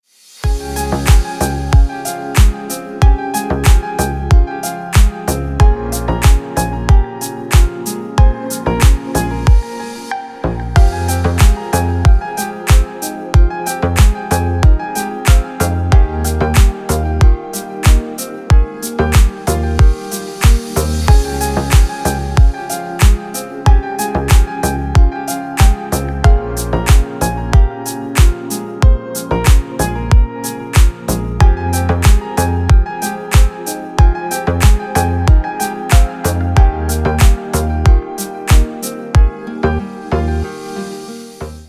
Приятная музыка на будильник
рингтоны на будильник